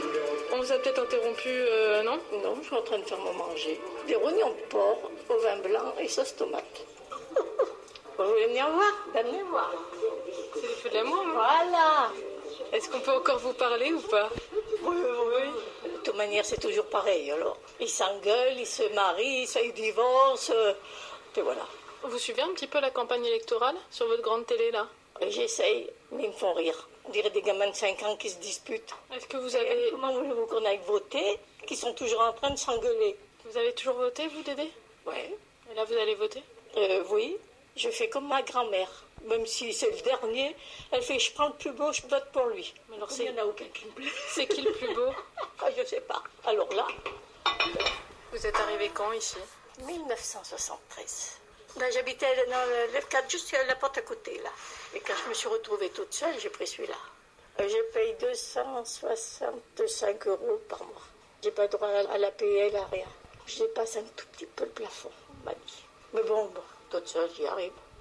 Mais je vous laisse plutôt écouter quelques témoignages, entendus à la radio, au fil des semaines, avant tout ce bazar électoral. Une vieille dame d’abord, qui a une étrange stratégie pour choisir !